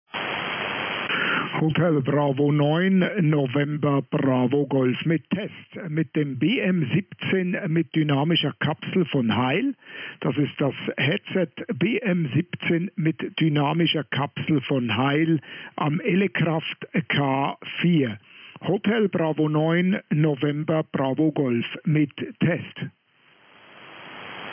Wir haben auch das BM-17 mit Dynamischer Kapsel eingehend an unserem K3S von ELECRAFT getestet – hören Sie sich das Ergebnis anhand unserer
Test-Aussendung mit dem BM-17 DYN und angepassten Mikrofon-Equalizer-Einstellungen am K4 an.